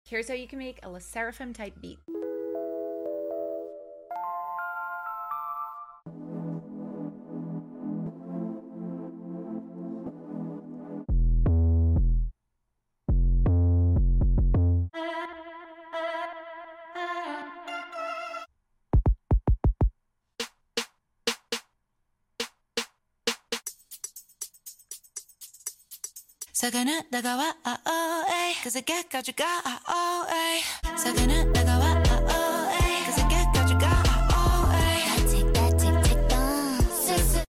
type beat!